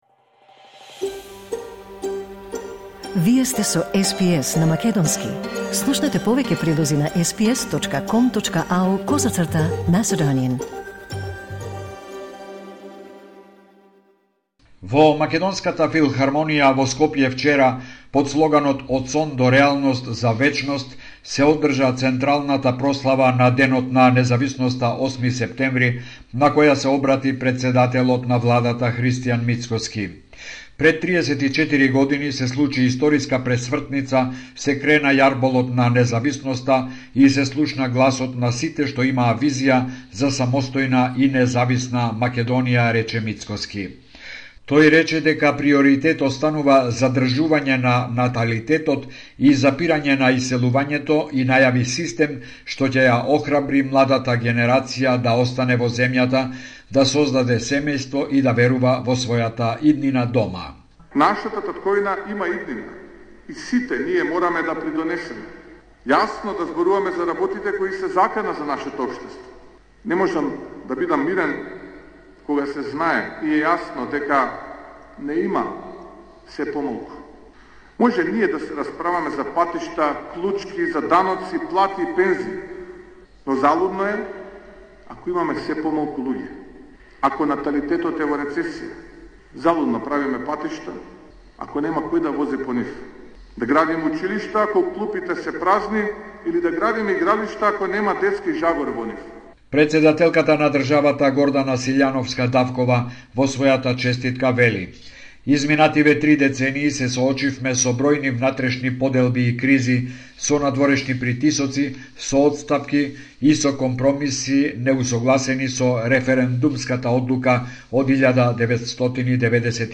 Извештај од Македонија 9 септември 2025